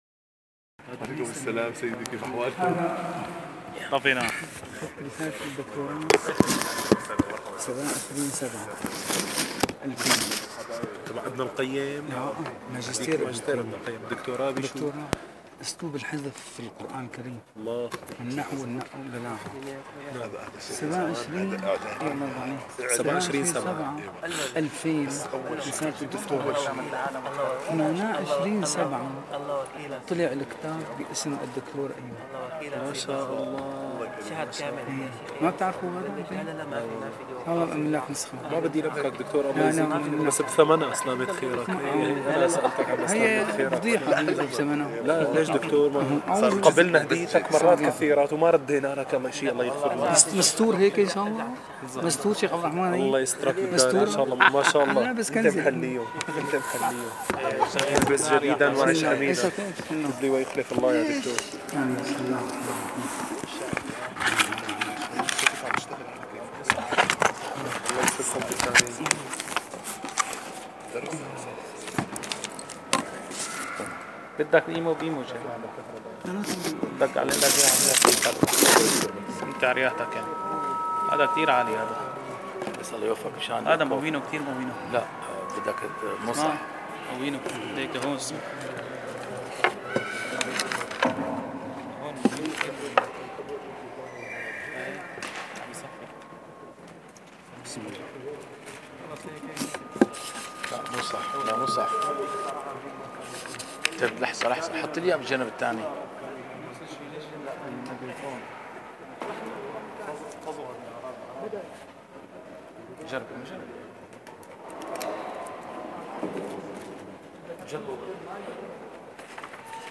- الدروس العلمية - شرح كتاب شذور الذهب - 57- شرح كتاب شذور الذهب: تتمة المبتدأ والخبر